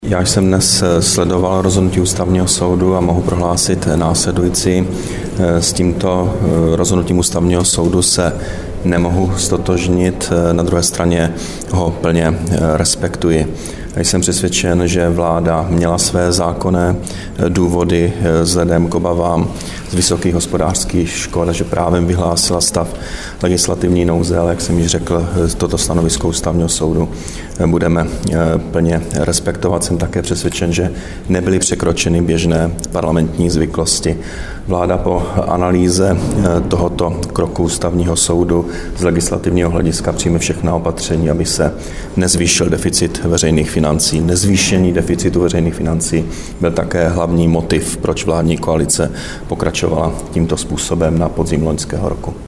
Komentář premiéra k rozhodnutí Ústavního soudu